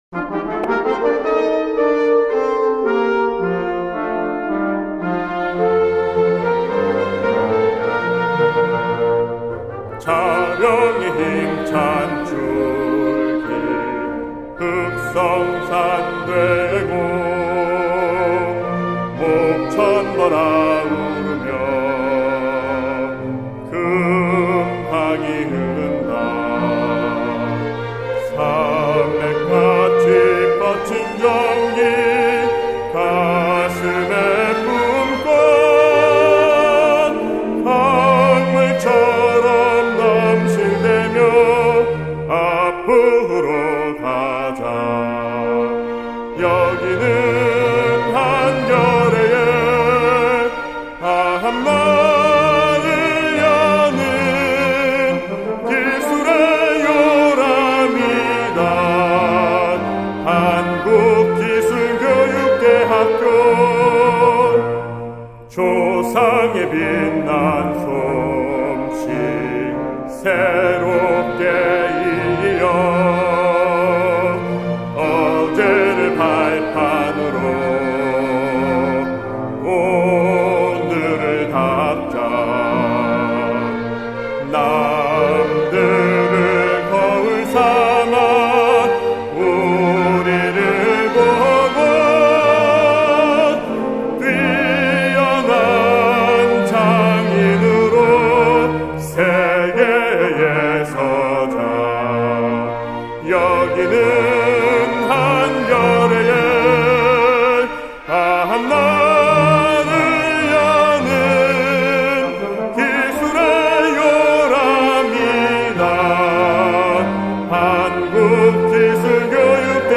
교가 | KOREATECH 소개 | 대학소개 : 한국기술교육대학교